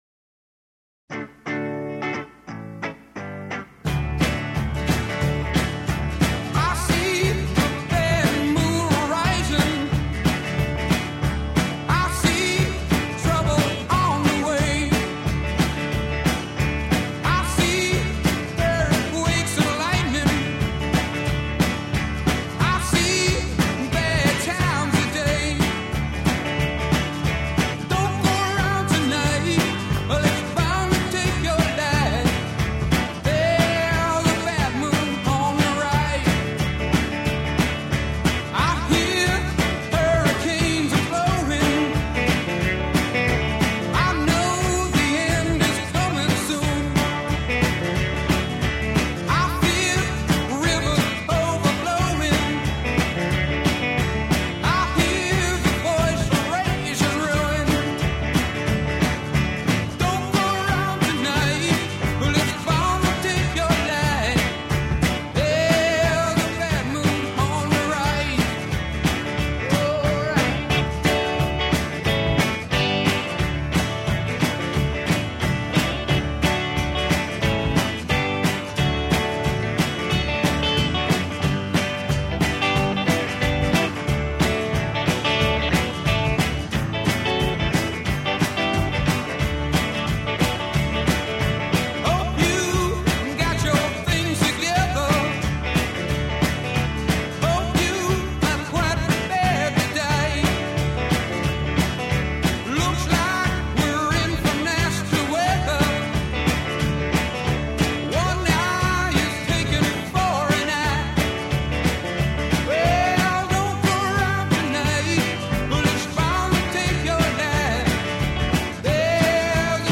Рок музыка Рок Rock